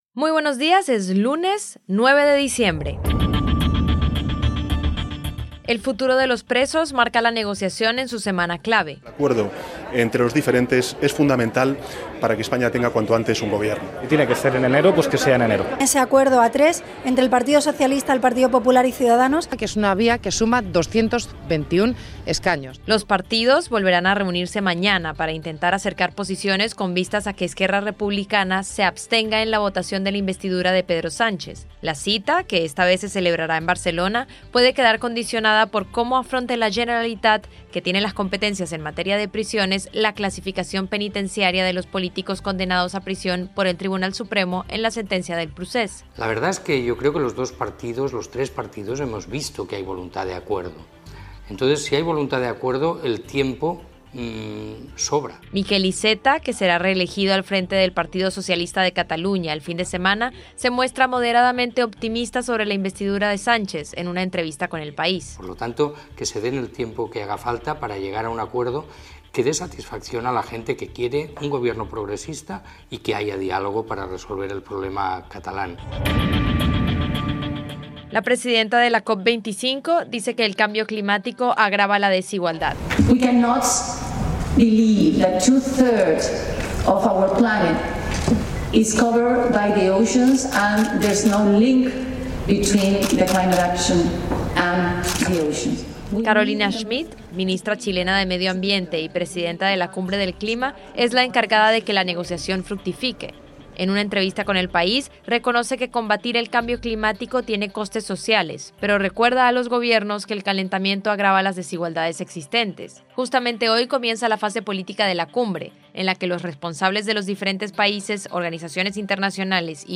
Boletín informativo en audio que recoge la actualidad del periódico